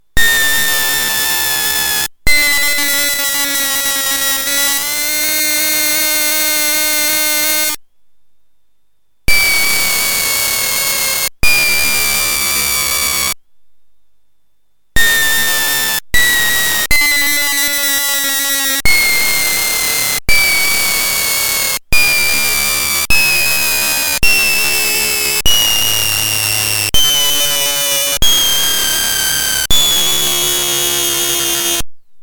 Во время написания программы для микроконтроллера было создано много смешных звуков, например эти:
scary-sounds-2.mp3